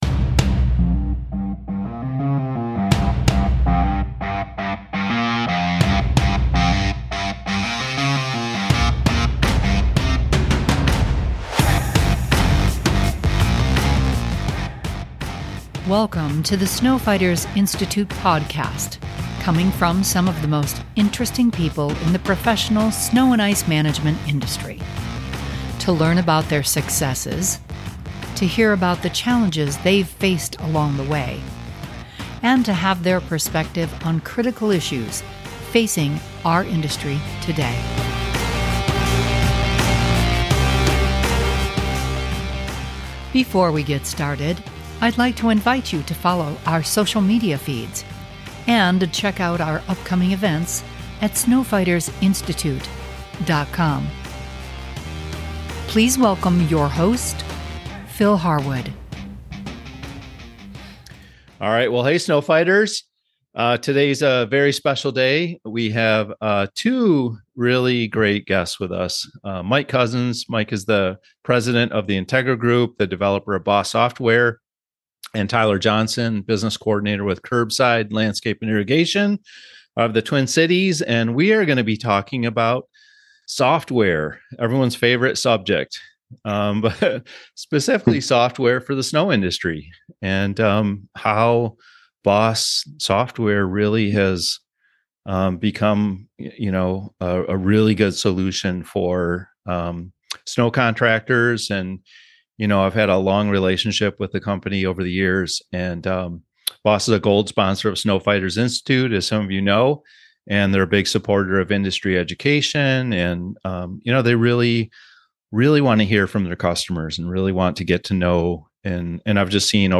By making significant investments in its capabilities and by engaging with Boss users involved in snow, Boss has developed a robust solution for snow contractors. This episode is insightful and fast-moving.